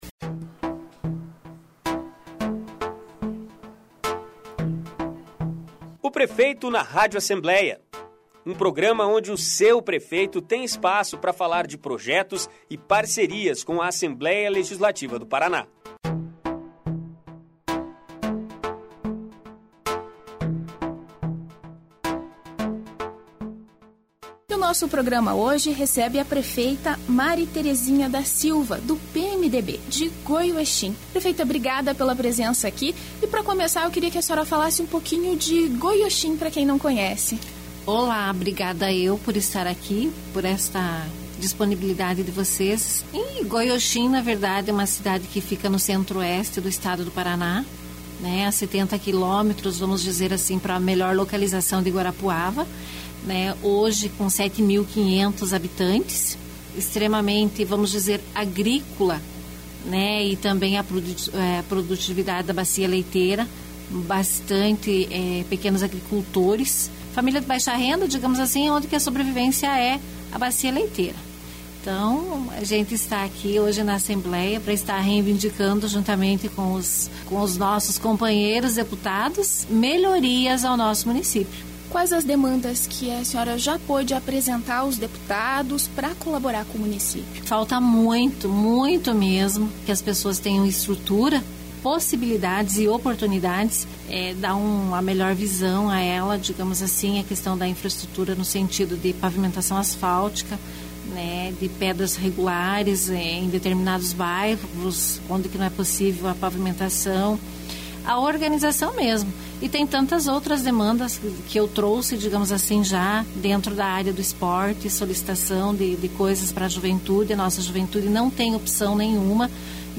Mari Terezinha da Silva, de Goioxim,é a entrevistada do "Prefeito na Rádio Alep"